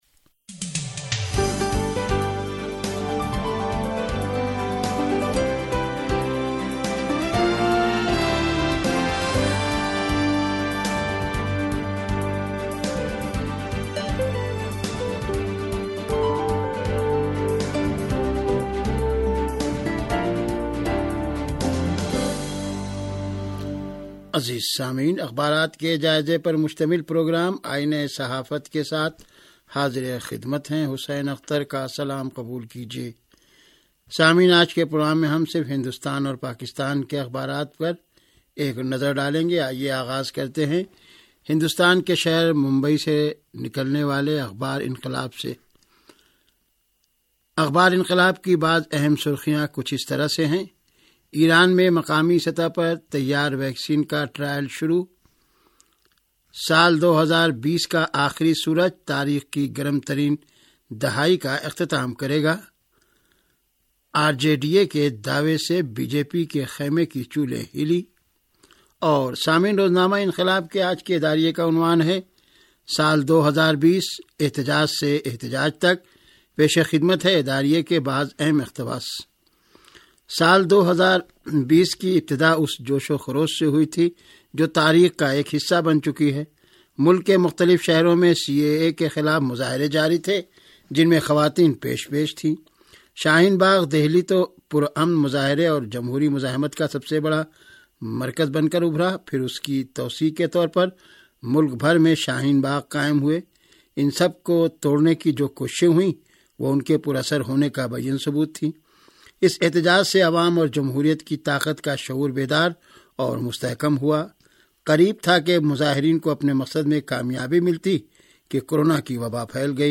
ریڈیو تہران کا اخبارات کے جائزے پرمبنی پروگرام - آئینہ صحافت